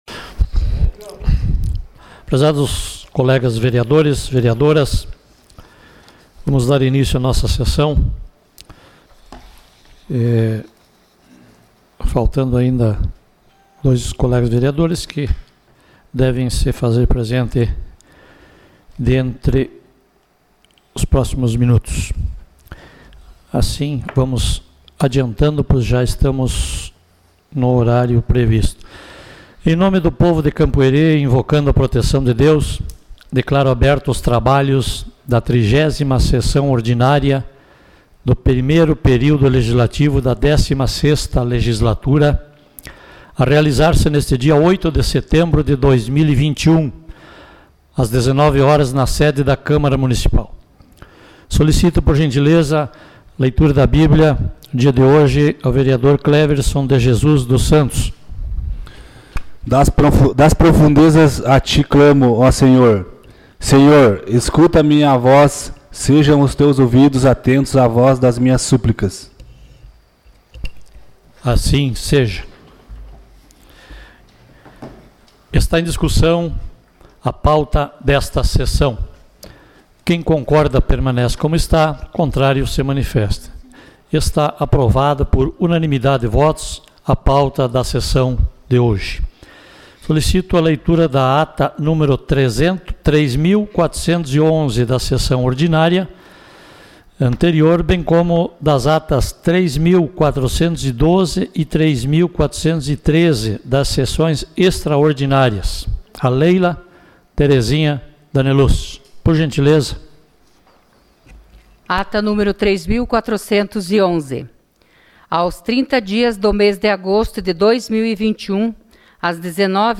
Sessão Ordinária dia 08 de setembro de 2021